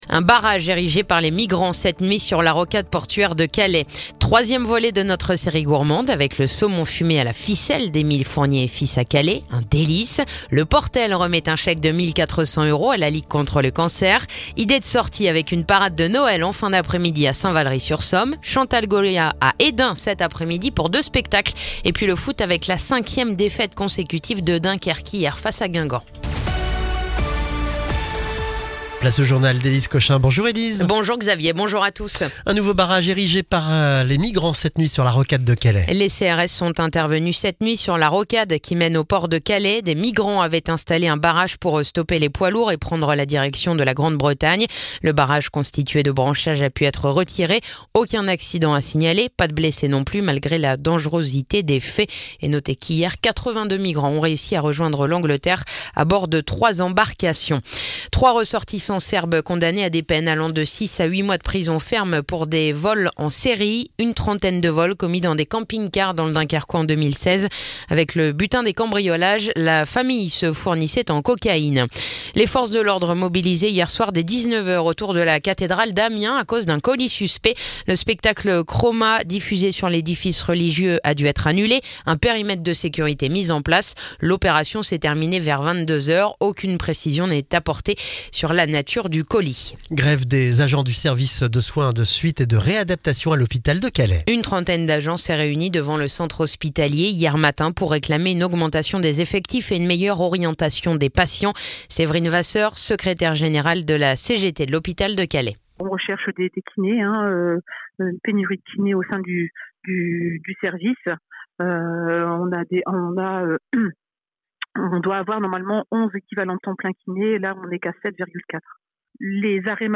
Le journal du mercredi 22 décembre